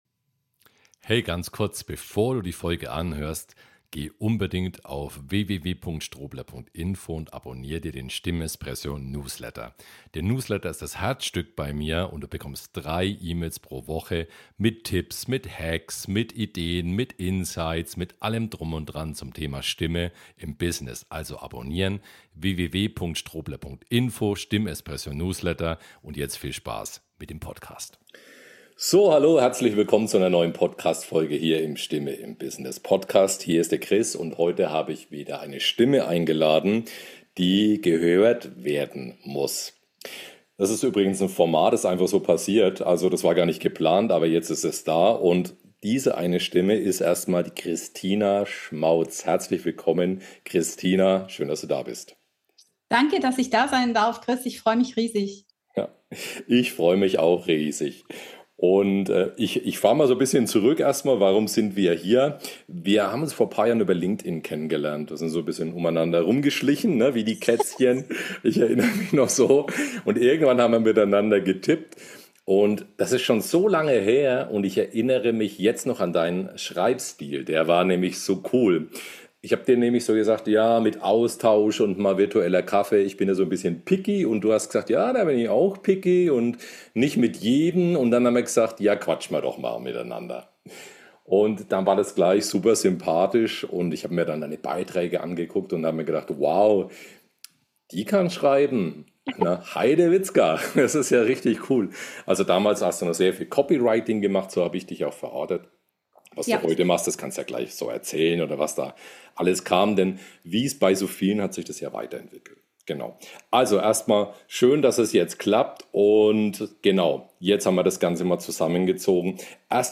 Hieraus entstanden ist ein schönes Interview, das ich dir nicht vorenthalten möchte.